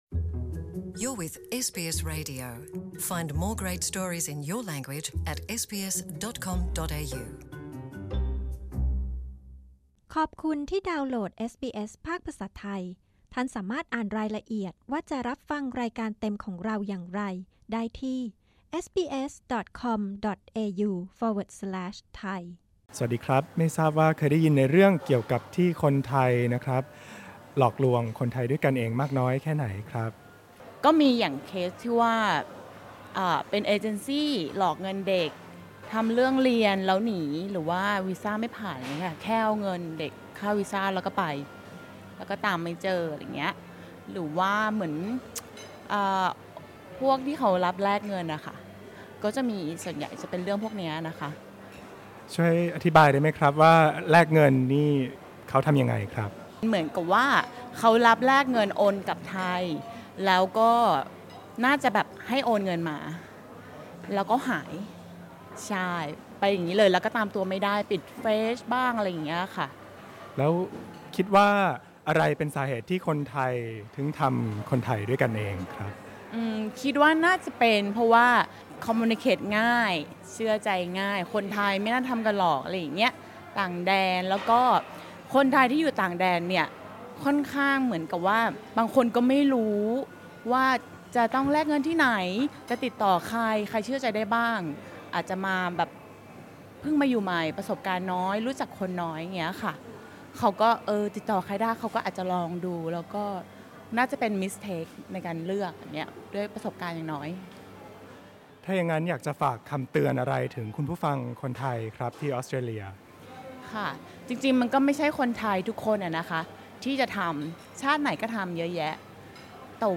เอสบีเอสไทยพูดคุยกับชาวไทยหลายท่านซึ่งอาศัยอยู่ในออสเตรเลียด้วยสถานการณ์ที่แตกต่างกันไป ว่าพวกเขาเคยประสบพบเห็นหรือได้ยินเรื่องราวของคนไทยในออสเตรเลียซึ่งหลอกลวงกันเองอย่างไรบ้าง เพื่อเป็นอุทธาหรณ์ให้ทุกๆ คนได้ระมัดระวังตัว ซึ่งปรากฏว่าเรื่องที่ชุมชนไทยเล่าให้เราฟังนั้นก็น่าสะพรึงไม่น้อยเลยทีเดียว
podcast_audio_thai_scam_vox_pop.mp3